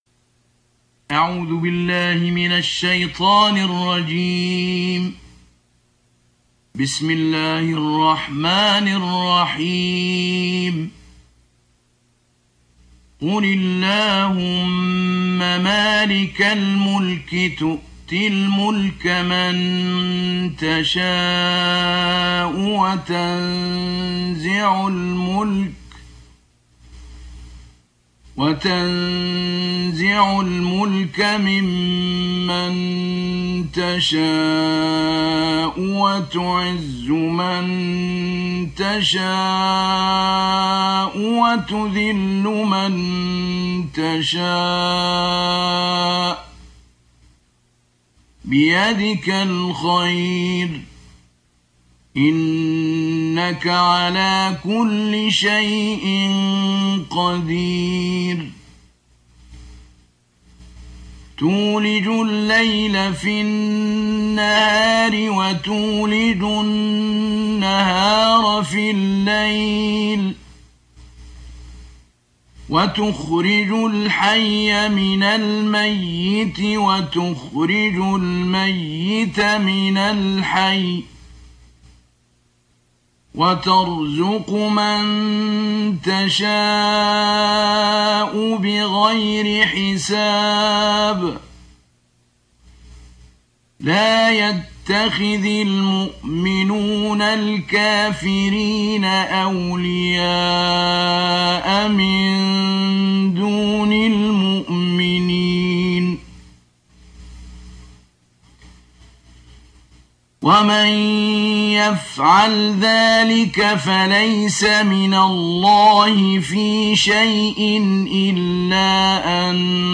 سجل الشيخ محمود البنا رحمه الله هذا المصحف المرتل النادر للاذاعة السعودية في أواخر أيامه فقد توفي بعد تسجيلة بمدة قصيرة ( بضعة اسابيع ) وهو طبعا مختلف عن مصحفه الآخر المسجل للاذاعة المصرية